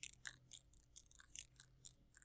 animalworld_spider.ogg